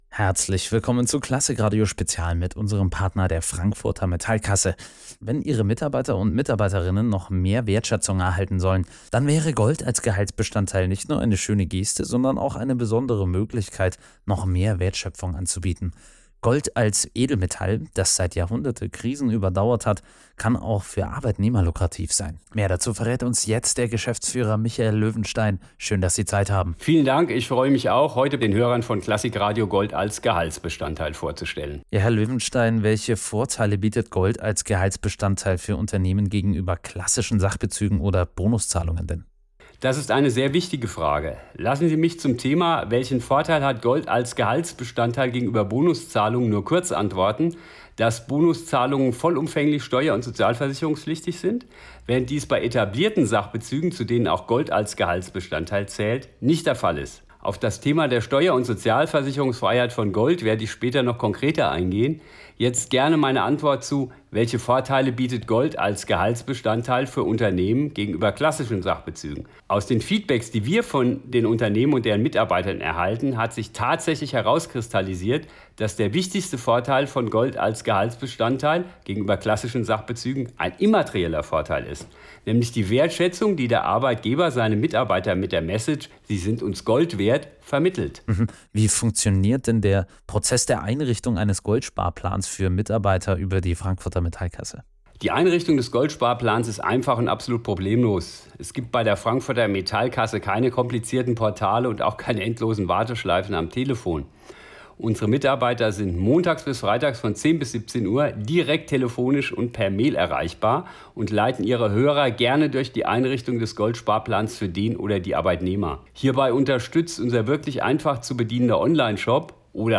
Interview-mit-Klassik-Radio-Teil-1.mp3